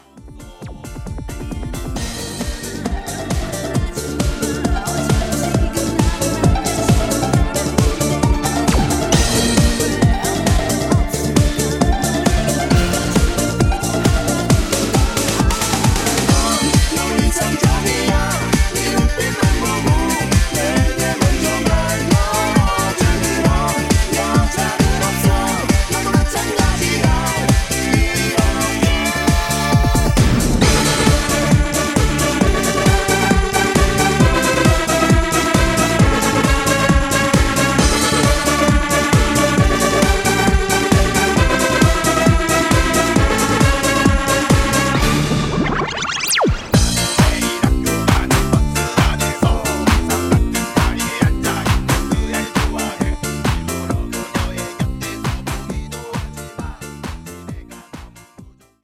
음정 -1키 3:13
장르 가요 구분 Voice MR